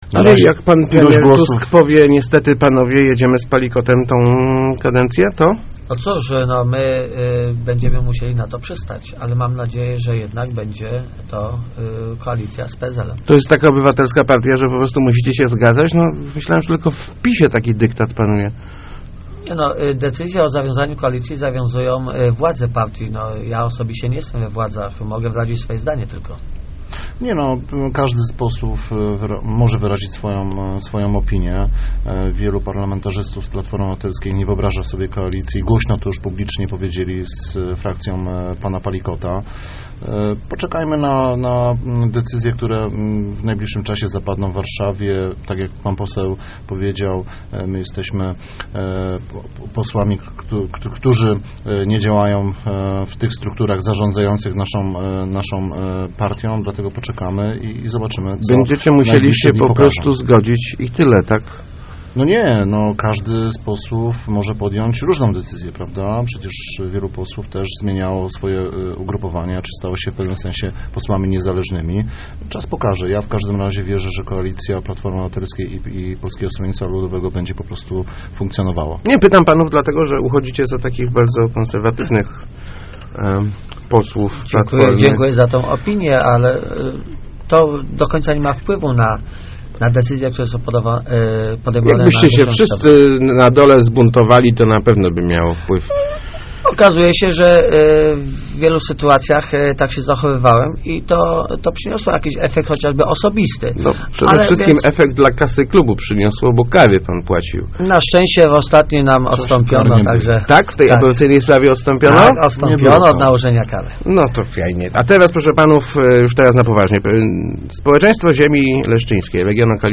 Z pewnością "Piątka" zostanie zakończona - zapewniali w Rozmowach Elki posłowie PO Wojciech Ziemniak i Łukasz Borowiak. Zobowiązali się też do interwencji w sprawie pieniędzy na chorych na raka w leszczyńskim szpitalu.